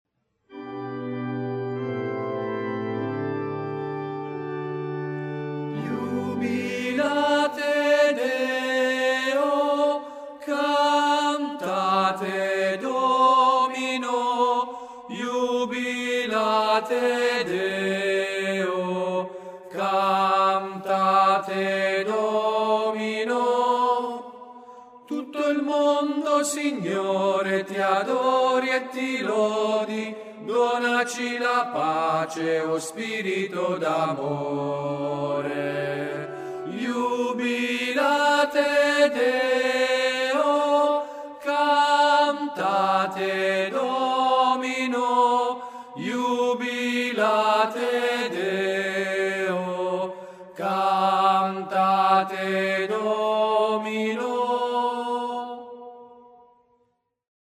03tenori.MP3